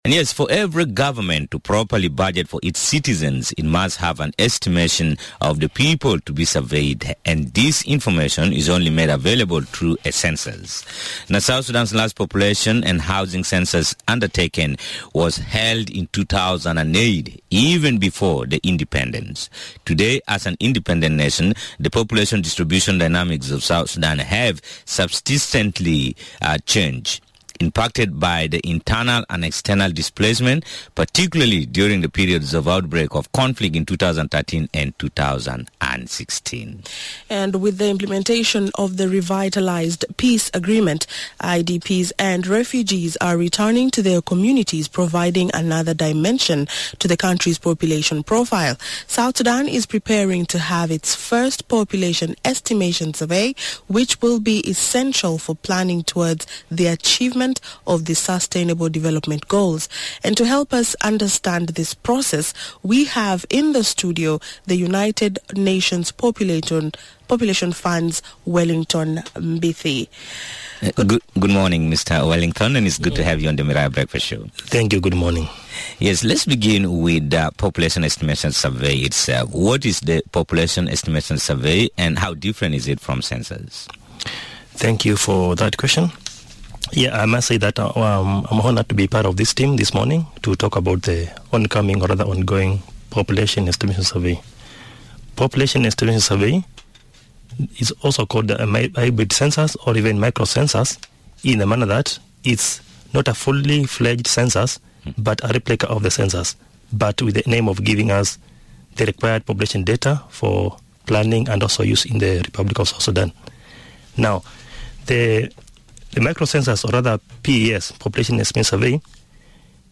To serve its population effectively, South Sudan has decided to conduct population estimation survey which begins today. Radio Miraya this morning featured one of the major stakeholders in the survey.